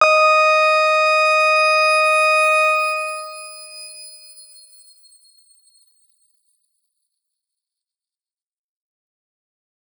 X_Grain-D#5-mf.wav